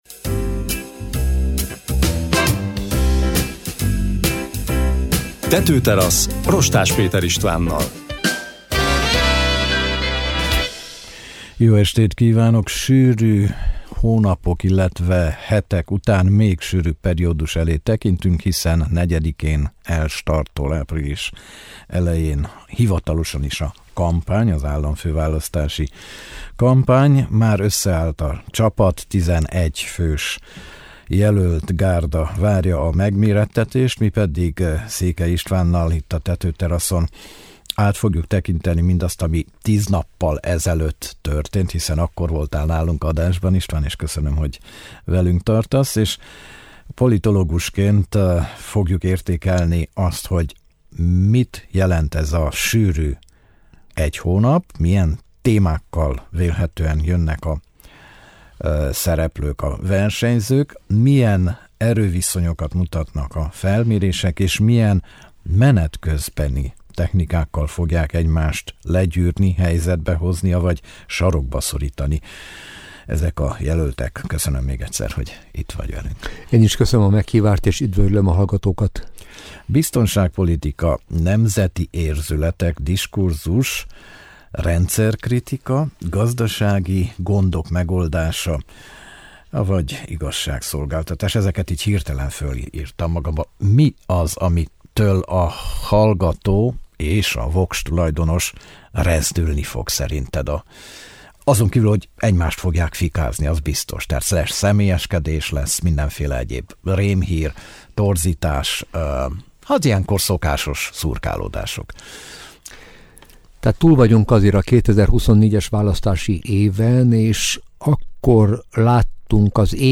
aki politológusként elemzett a stúdióban.